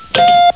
ButtonPush.wav